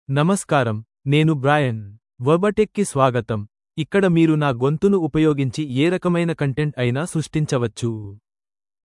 Brian — Male Telugu AI voice
Brian is a male AI voice for Telugu (India).
Voice sample
Listen to Brian's male Telugu voice.
Male
Brian delivers clear pronunciation with authentic India Telugu intonation, making your content sound professionally produced.